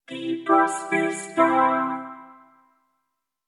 Soundeffekte